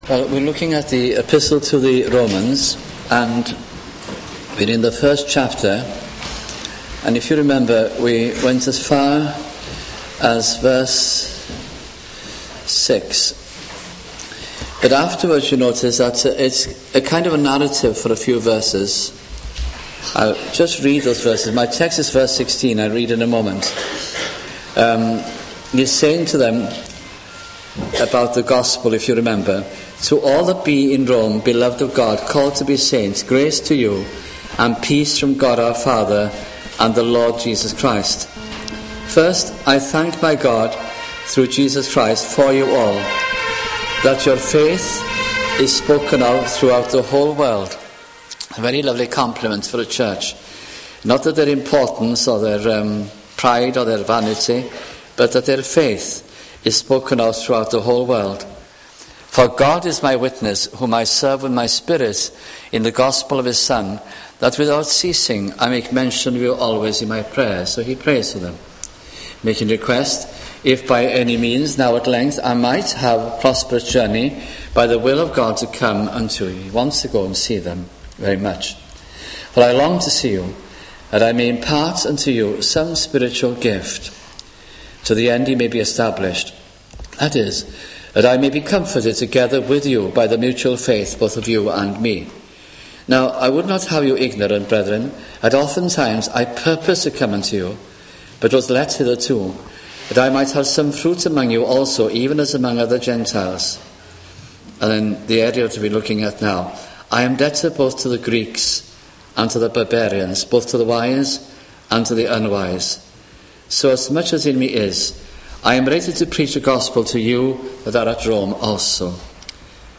» Romans 1996-98 » sunday morning messages